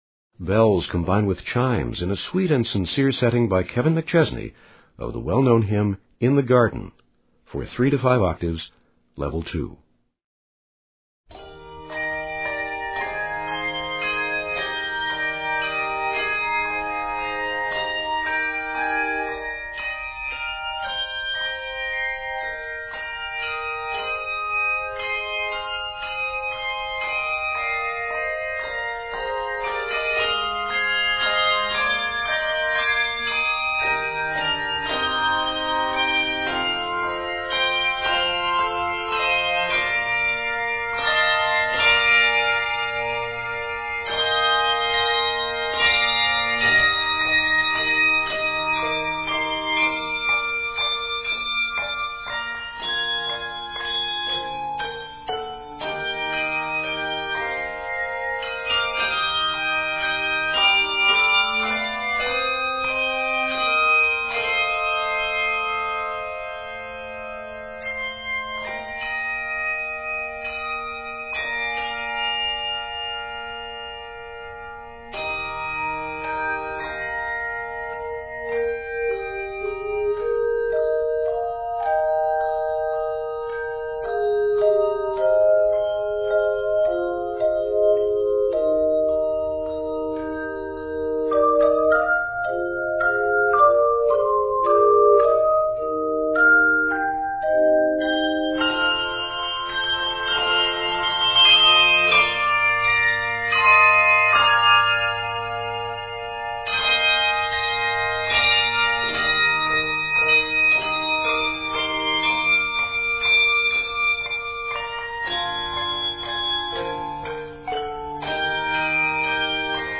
3,4 and 5 octaves of bells combine with 3
optional chimes